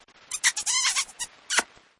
Tesla Locking Sounds
JM_Tesla_Lock-Sound_Cartoon-Kiss_Watermark.mp3